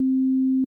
Sine wave
Audio: YM2612 sine wave
fm-sine.mp3